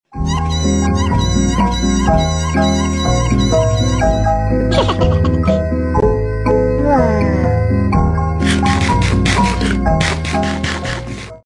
Yippee 😃 sound effects free download